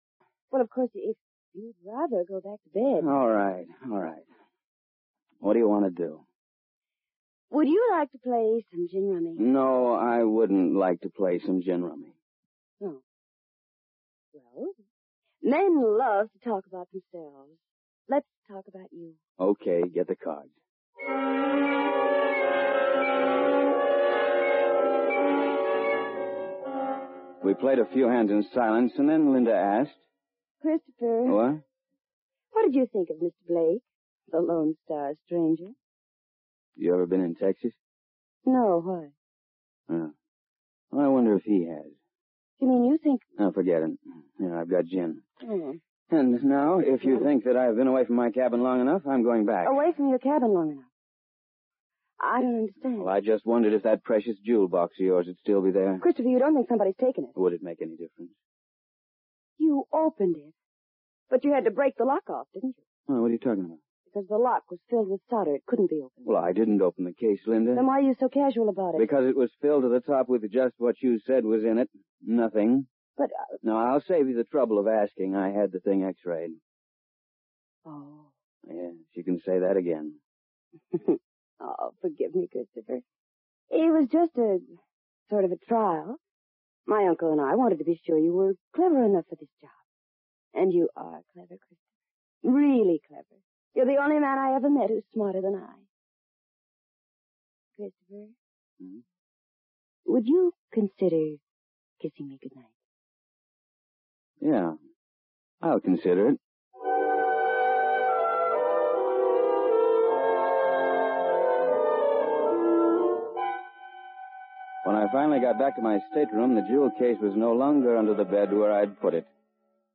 Old time radio fans, please enjoy the free internet radio station, "Hank's Gumshoe O.T.R.", where the best of the bygone radio detectives are featured and streamed around the world 24/7.